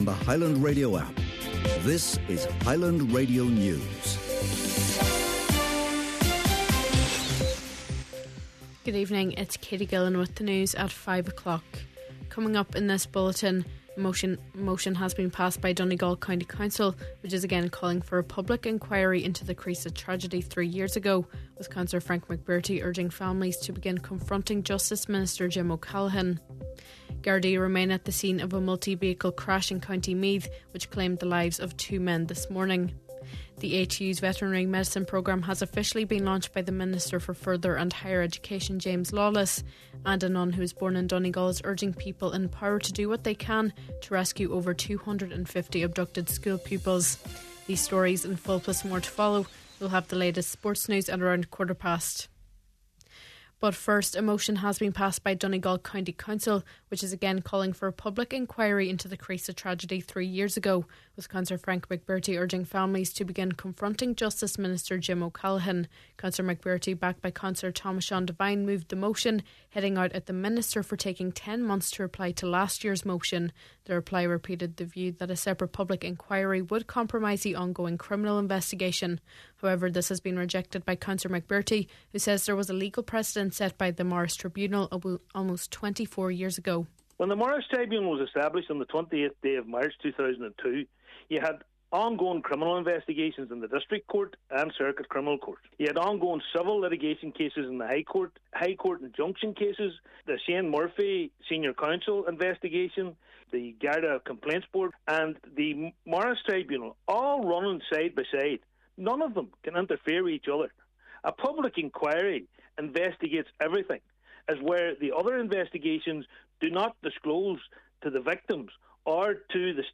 Main Evening News, Sport and Obituary Notices – Monday November 24th